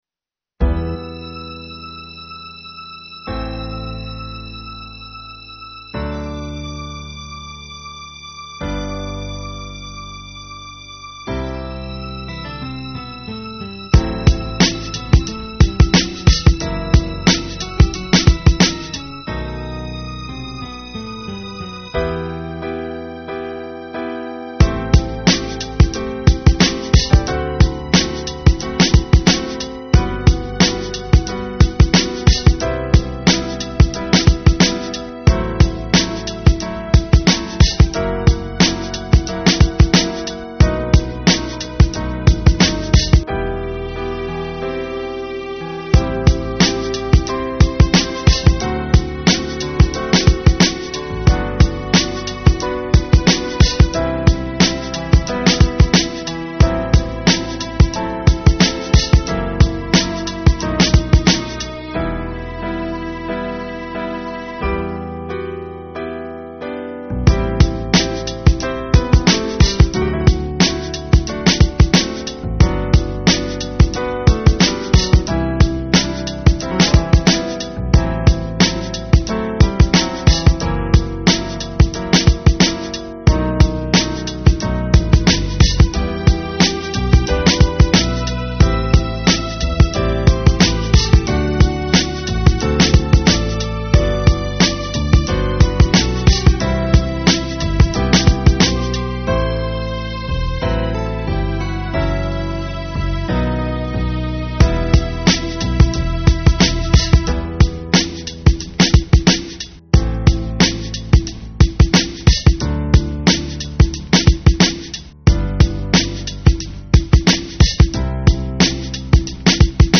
У кого как начинались увличения Вот и вспомнил сегодня 98-99 когда я начинал слушать РэП и написал по этому поводу воть Такой Минус ...
Правда Качество Потерялось После Зажимки Объем А то большой просто был ...;(